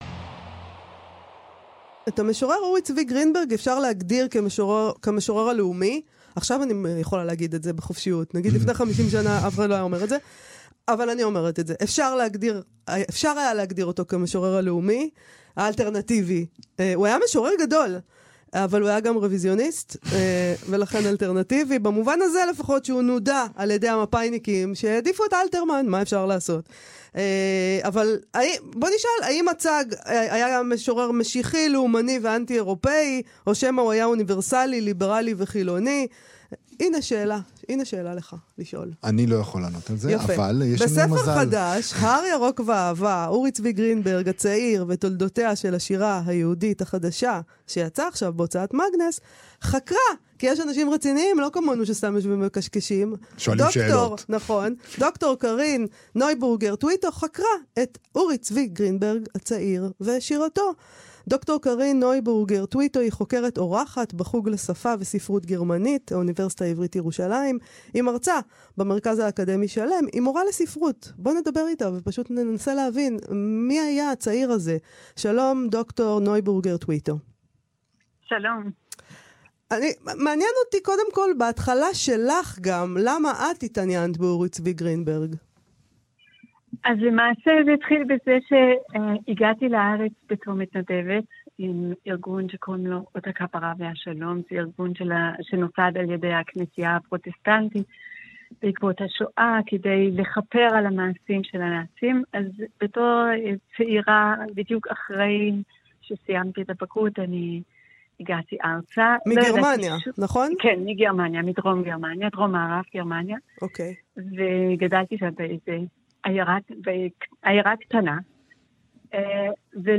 כאן-תרבות, "מה שכרוך" ריאיון